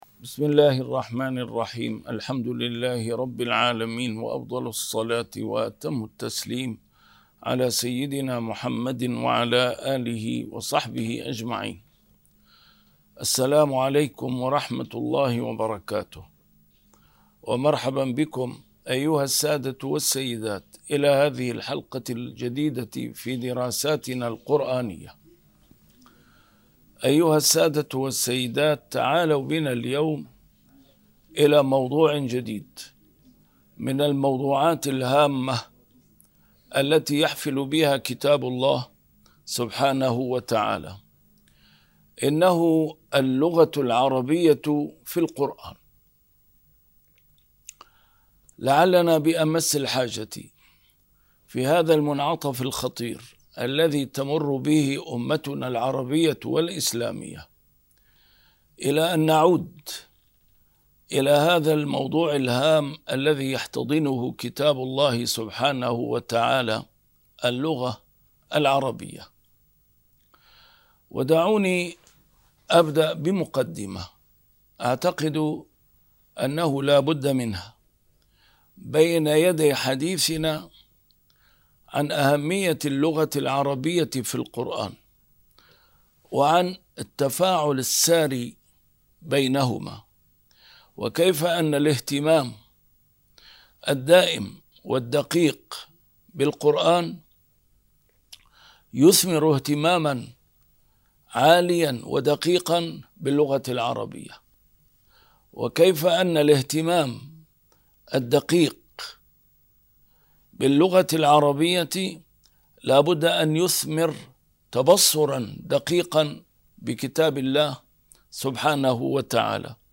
نسيم الشام › A MARTYR SCHOLAR: IMAM MUHAMMAD SAEED RAMADAN AL-BOUTI - الدروس العلمية - اللغة العربية في القرآن الكريم - 1- كيف أكسب القرآن الكريم اللغة العربية الديمومة؟